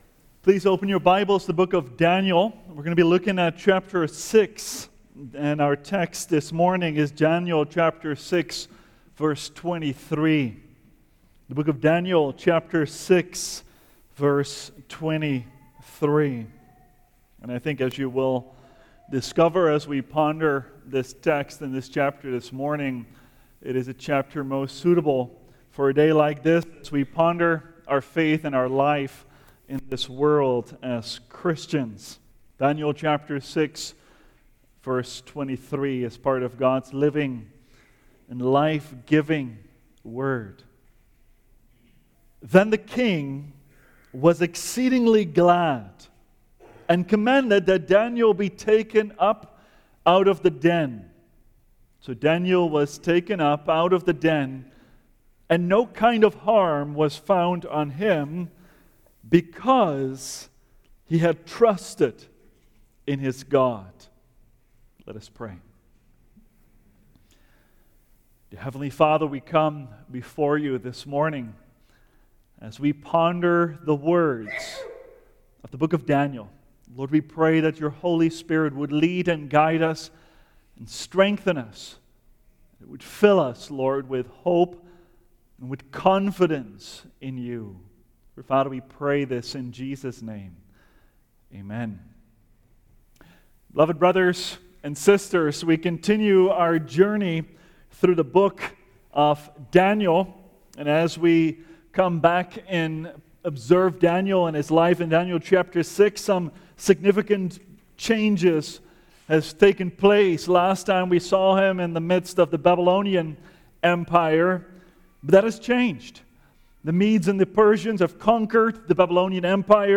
The Man of God among the Lions – Seventh Reformed Church